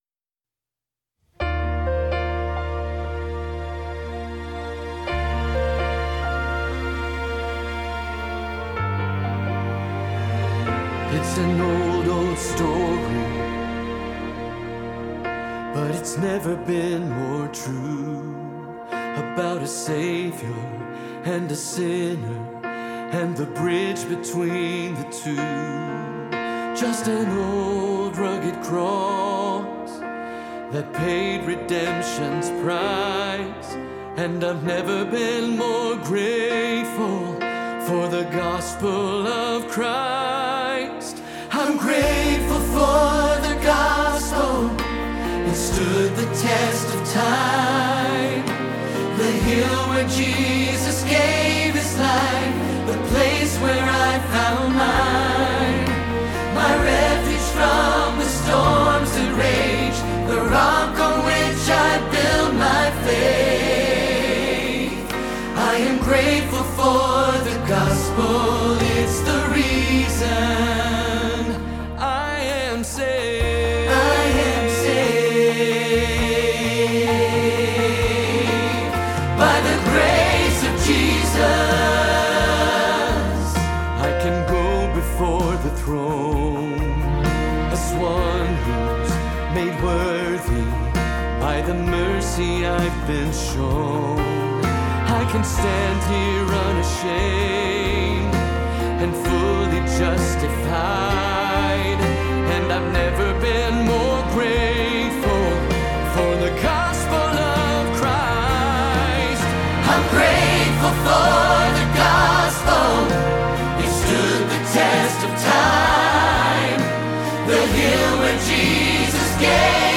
Grateful for the Gospel – Alto – Hilltop Choir
05-Grateful-for-the-Gospel-Alto-Rehearsal-Track.mp3